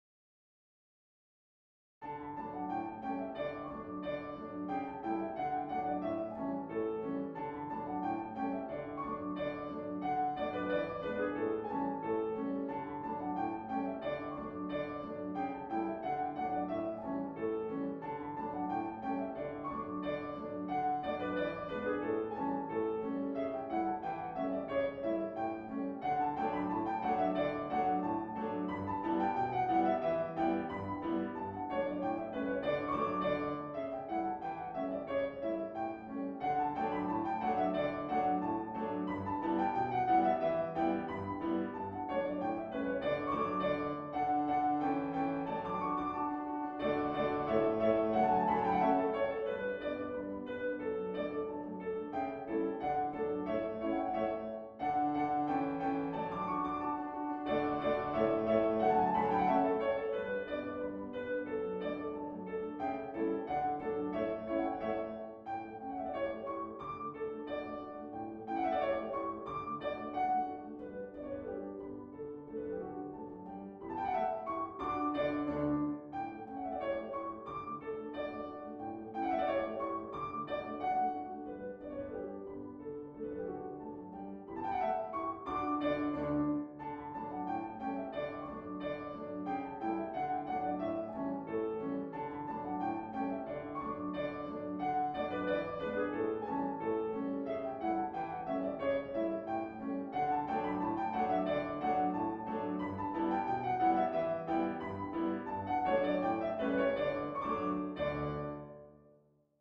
愛らしくも安定の４拍子、軽快で優雅な舞曲です。